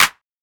kits/OZ/Claps/Clap (DoIt).wav at ts
Clap (DoIt).wav